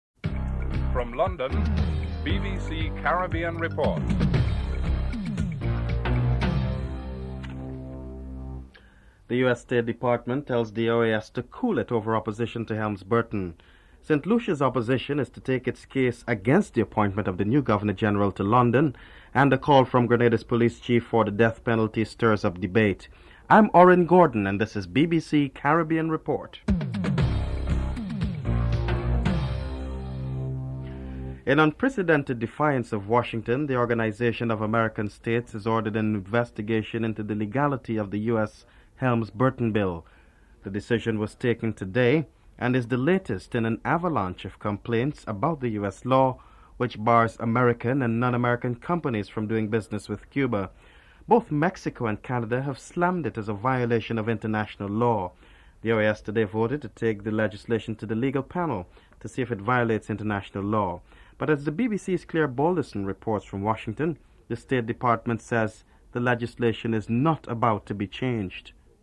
The British Broadcasting Corporation
1. Headlines (00:00-00:30)
2. The US State Department tells the OAS to cool it over opposition to Helms-Burton. US State Department spokesman Nick Burns and Canada's Ambassador Brian Dickson are interviewed.